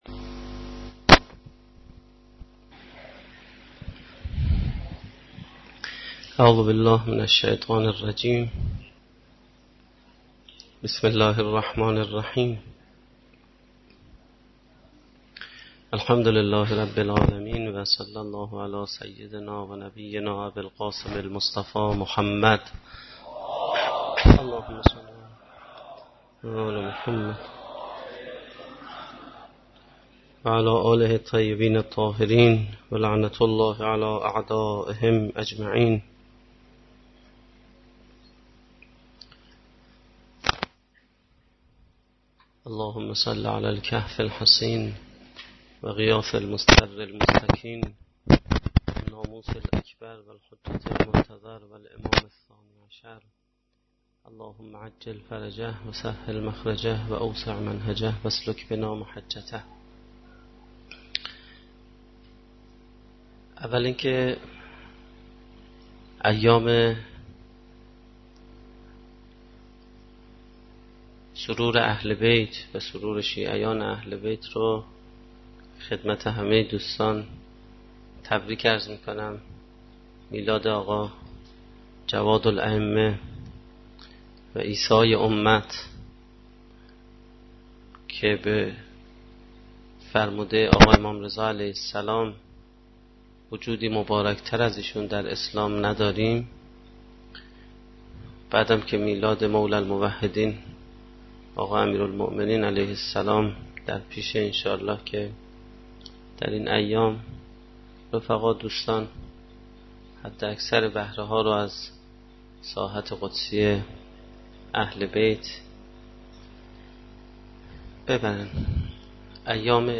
سخنرانی
در جلسه سیر و سلوک قرآنی